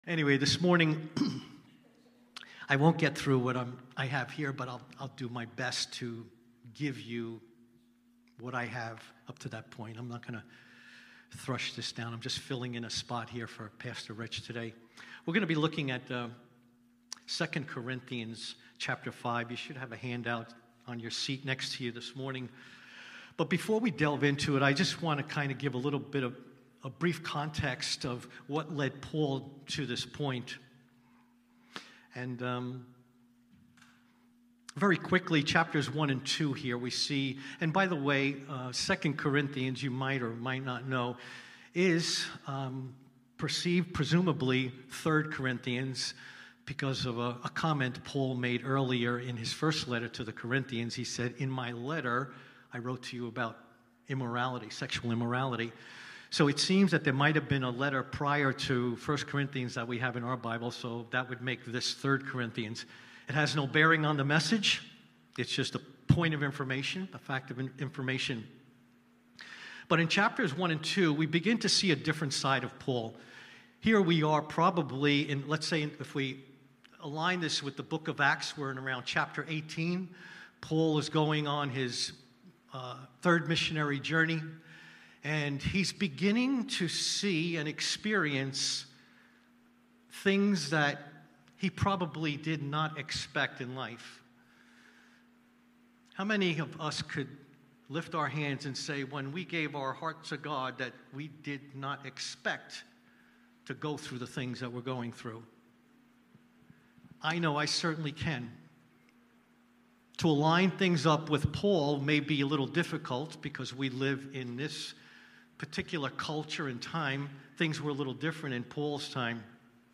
Topic Evangelism , Giving , Missions Book 2 Corinthians Watch Listen Save Cornerstone Fellowship Sunday morning service, livestreamed from Wormleysburg, PA.